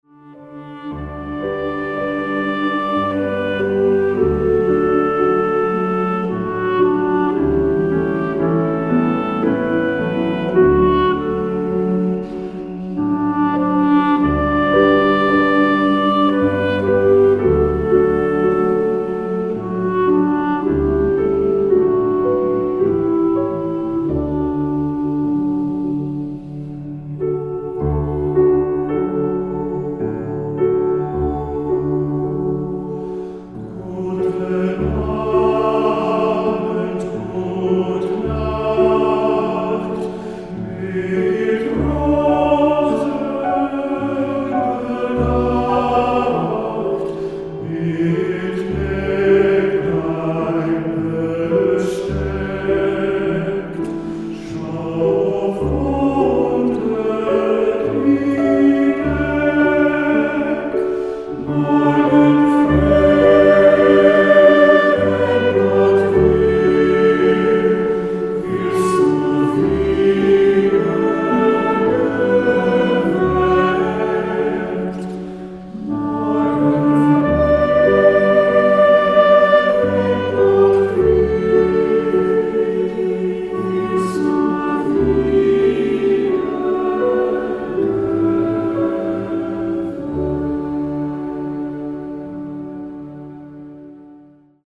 Choeur Mixte (SSATB) et Piano